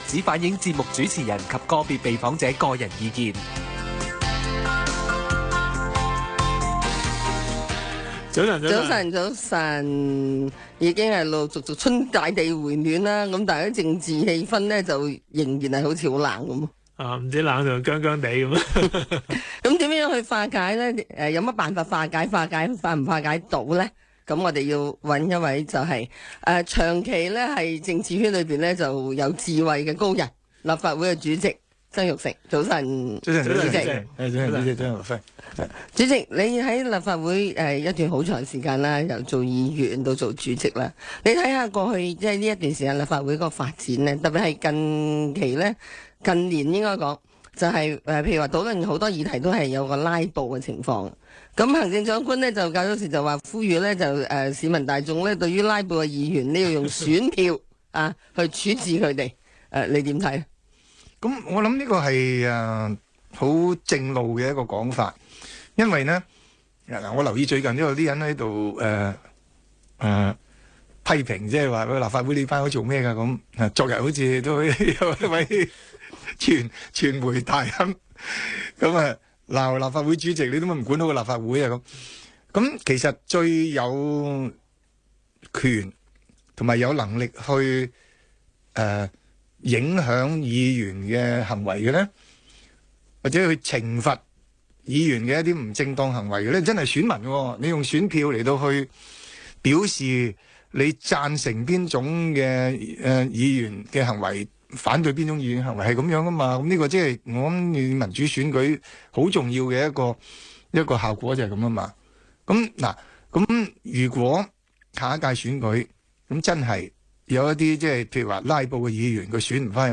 商業電台《政經星期六》訪問